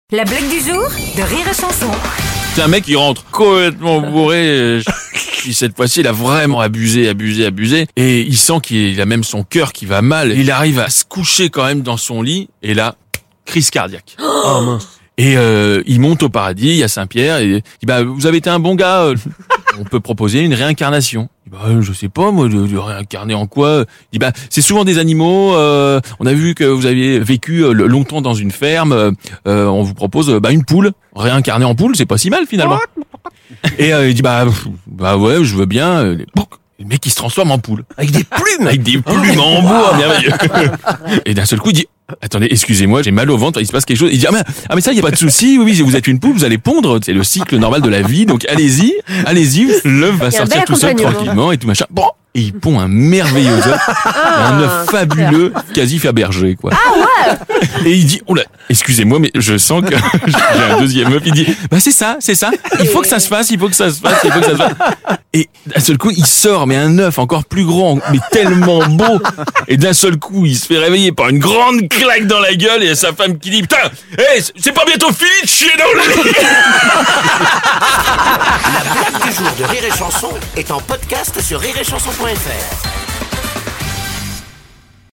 Le Journal du rire : L'intégrale de l'interview de Kev Adams pour parler de son spectacle "Miroir" - 14.04.2023